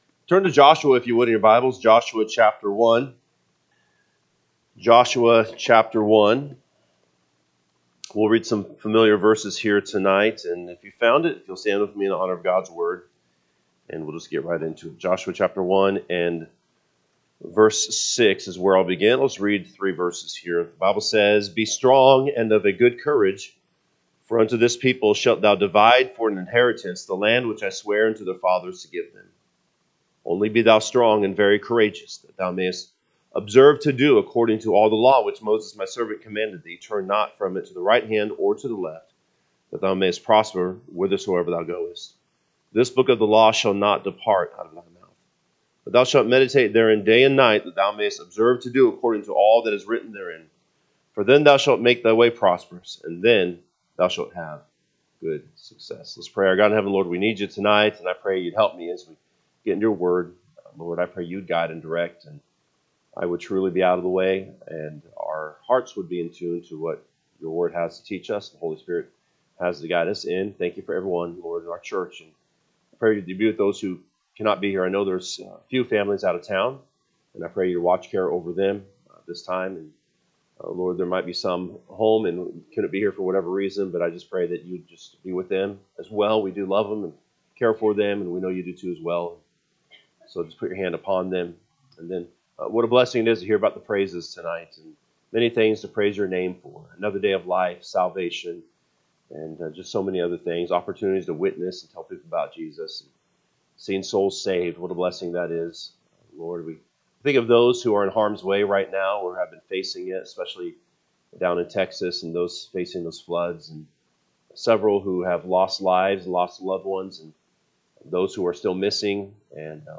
July 6, 2025 pm Service Joshua 1:6-9 (KJB) 6 Be strong and of a good courage: for unto this people shalt thou divide for an inheritance the land, which I sware unto their fathers to give them.…
Sunday PM Message